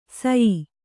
♪ sayi